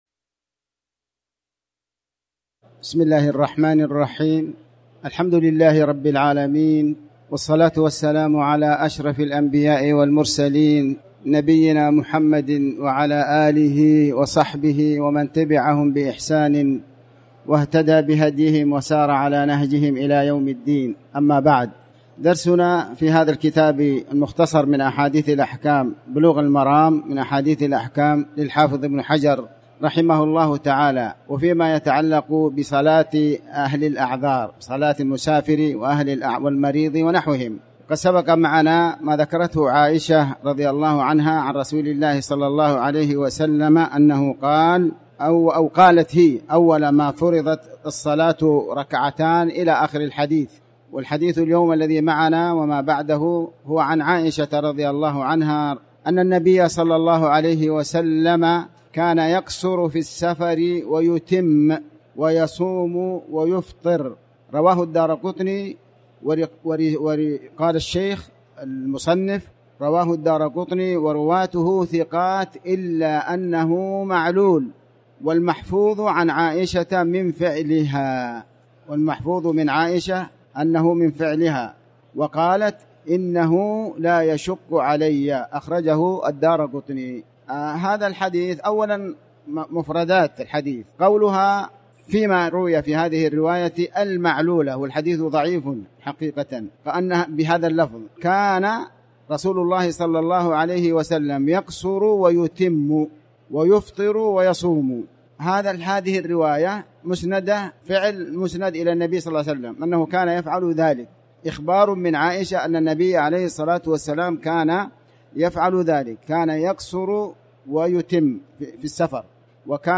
تاريخ النشر ٢٤ شوال ١٤٤٠ هـ المكان: المسجد الحرام الشيخ